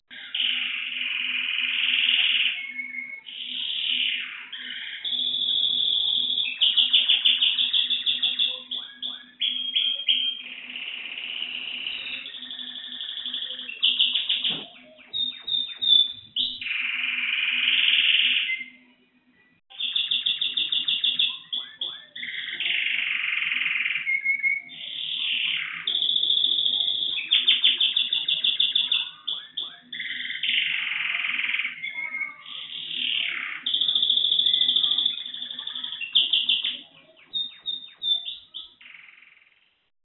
الالات واصوات